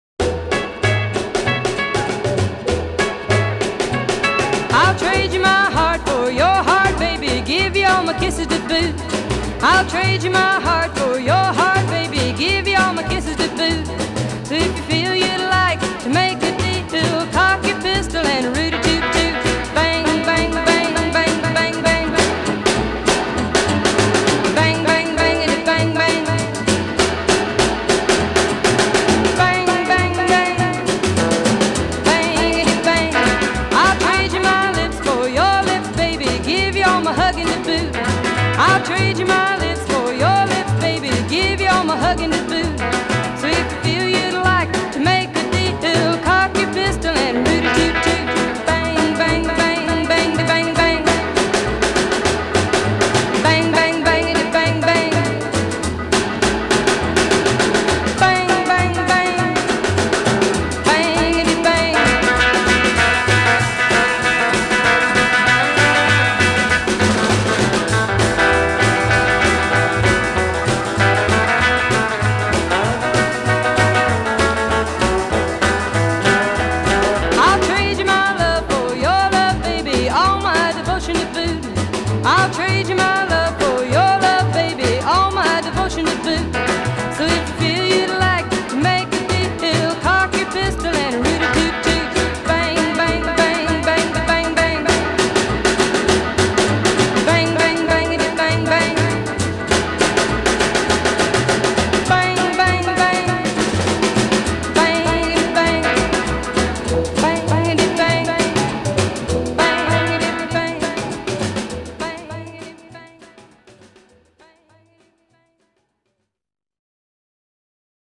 75 great tracks from the pioneers of Rockabilly.